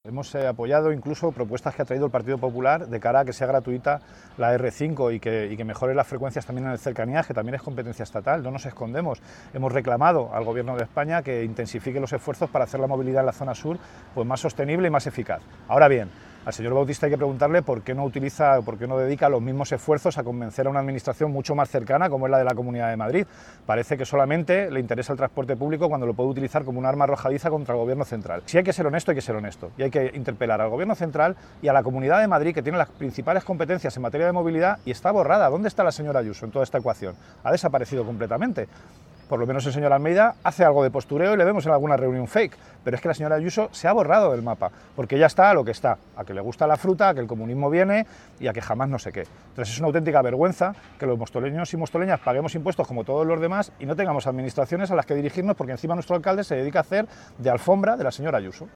declaraciones-emilio-delgado-A5.mp3